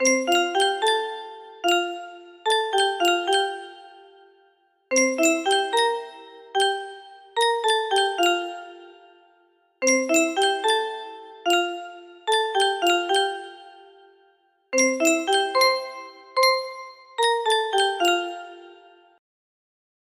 Down in the valley music box melody